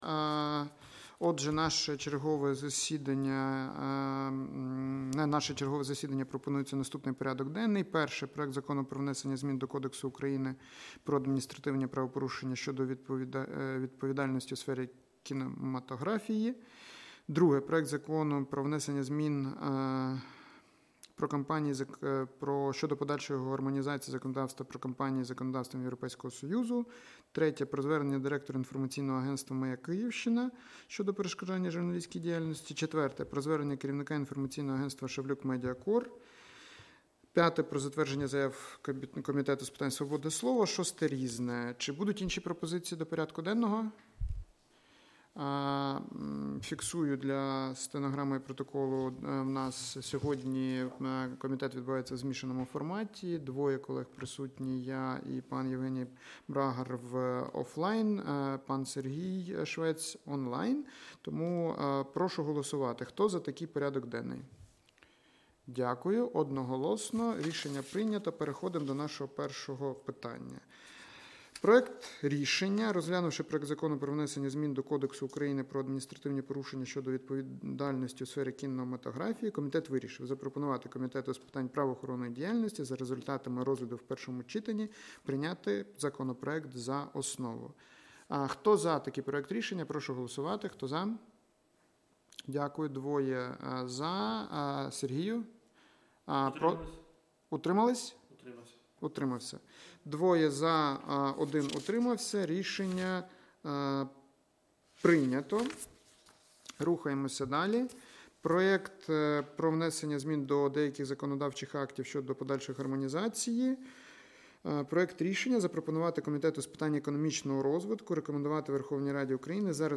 Аудіозапис засідання Комітету від 16 січня 2025р.